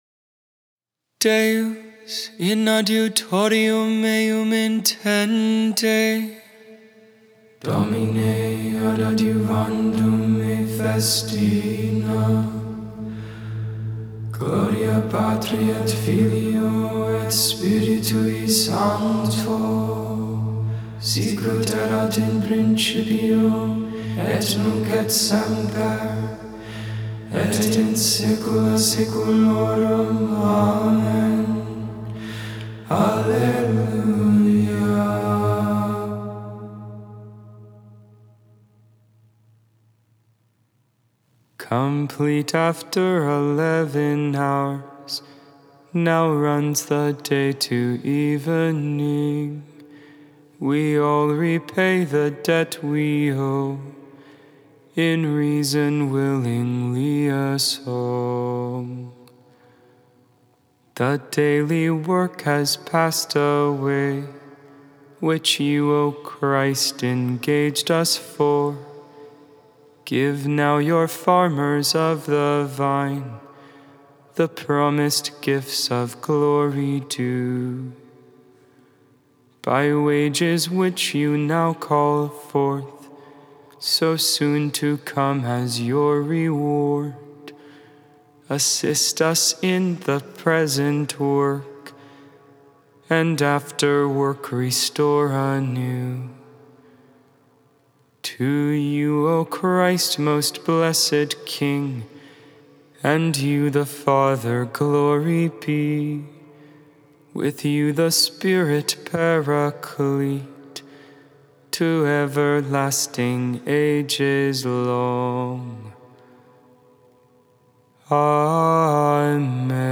Vespers, Evening Prayer for the 4th Friday in Ordinary Time, February 3rd, 2023. Memorial of St. Blaise.
(English, tone 8, final verse Latin)